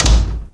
门的开启和关闭
描述：门开启和关闭几次的声音